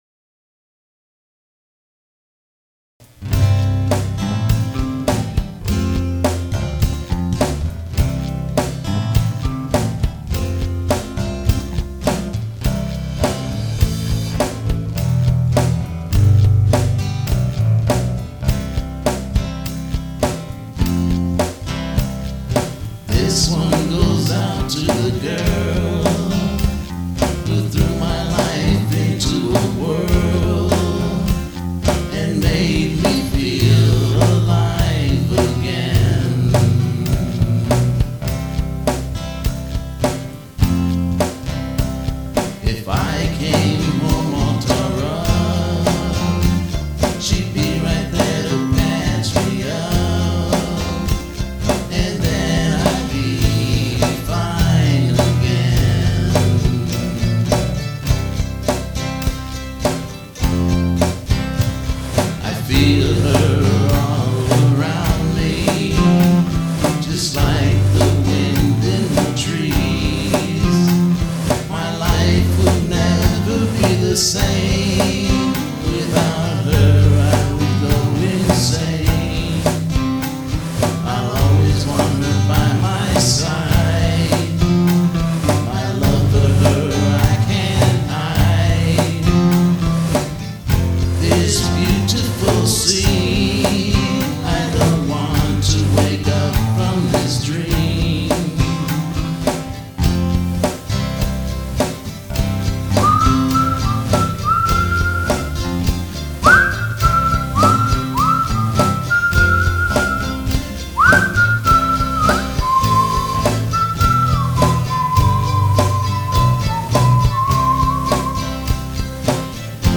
The lyrics go straight to any heart, the melodies spark